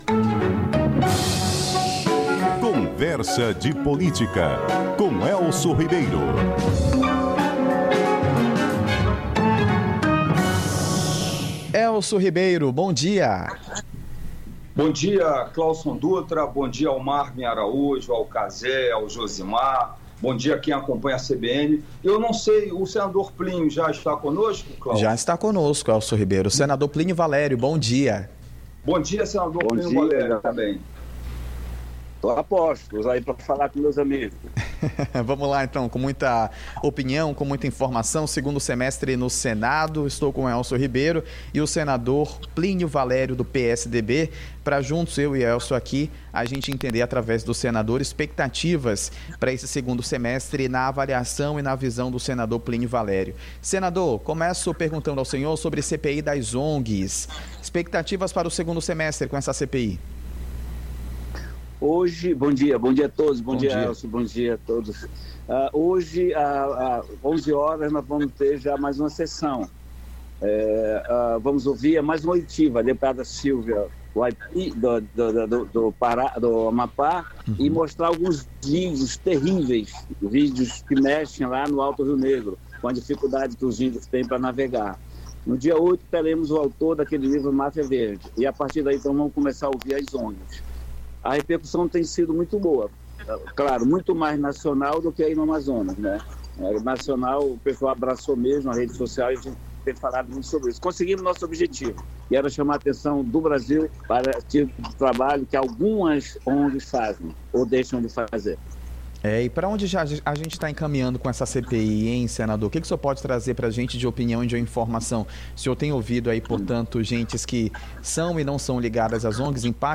ENTREVSITA_PLINIO_VALERIO_010823.mp3